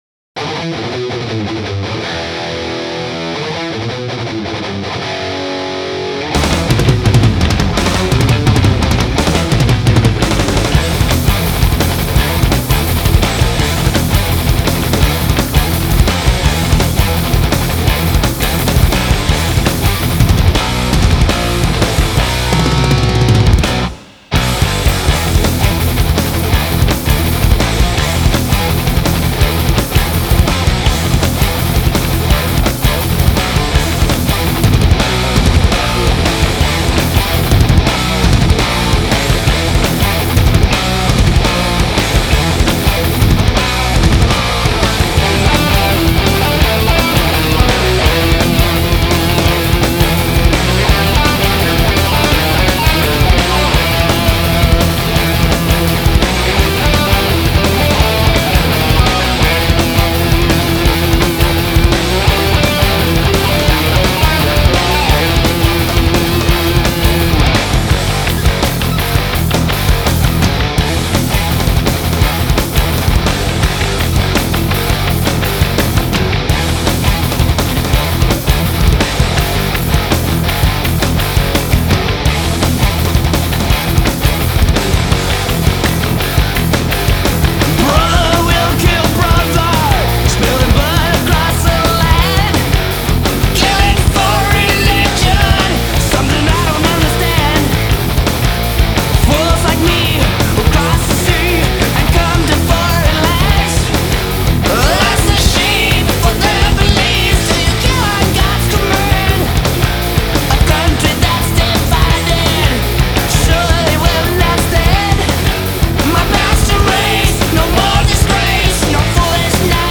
Thrash Metal / Heavy Metal